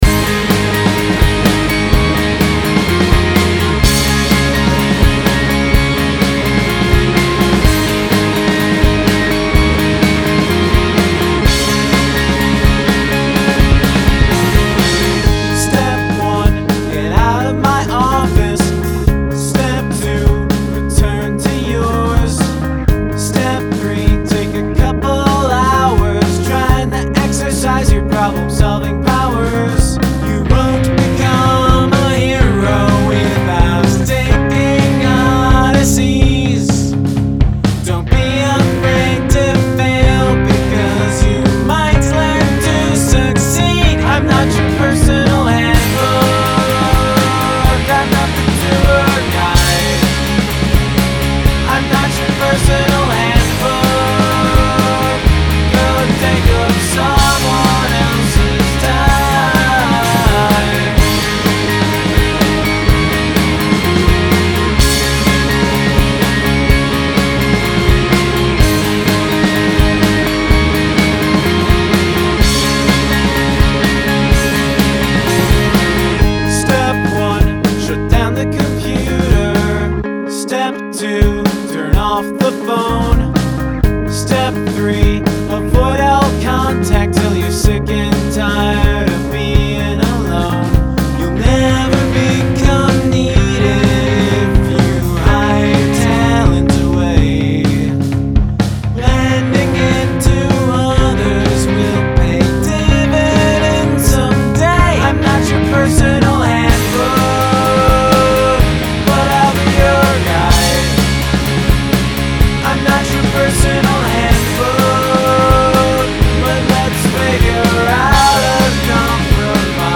GREAT instrumental intro
It’s all extremely catchy and pleasant musically.
The high guitar riff is catchy and fun.